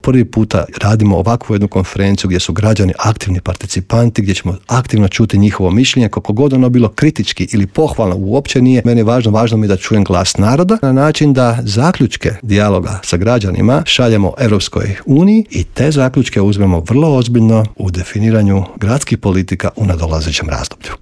U Intervjuu Media servisa gostovao je predsjednik zagrebačke Gradske skupštine, Joško Klisović, koji je istaknuo važnost konferencije, njezine ciljeve i objasnio koliko je bitna uloga građana.